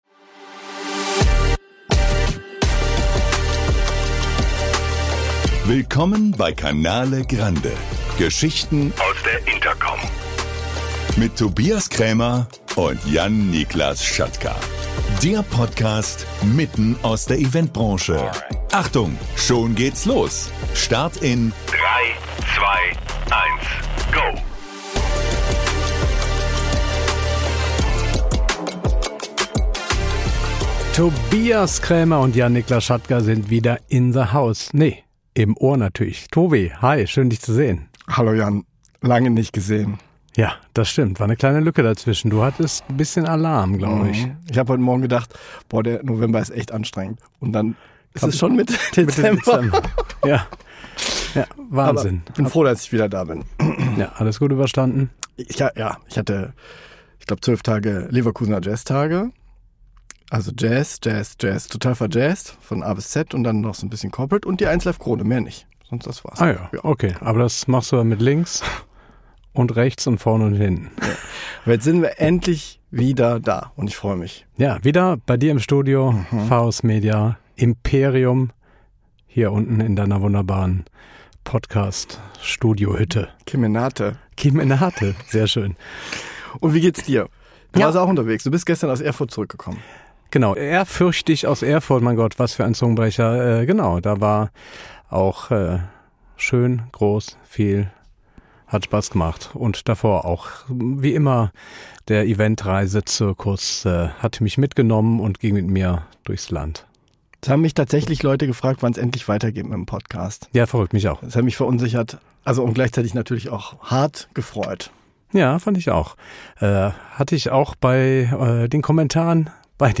Zwei Kollegen aus der Branche kommen dabei auch mit einem O-Ton zu Wort und bringen ihre Impulse ein.